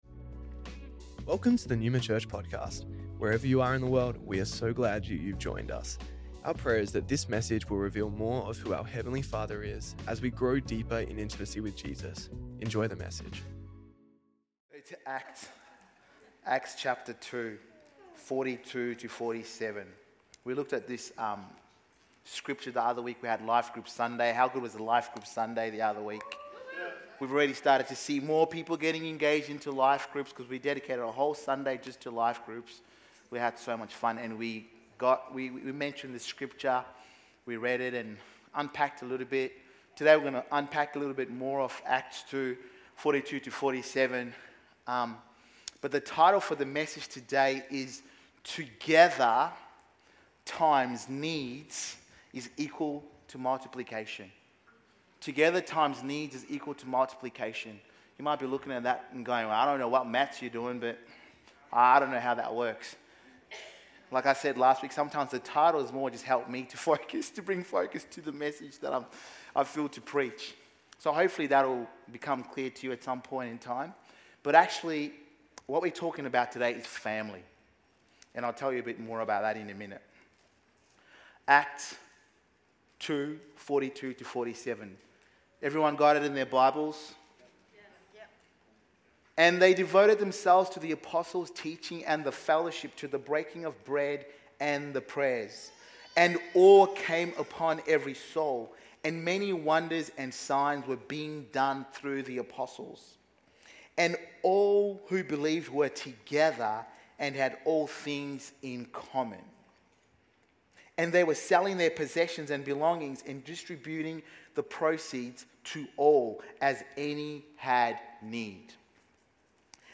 Neuma Church Melbourne South Originally Recorded at the 10AM service on Sunday 2nd March 2025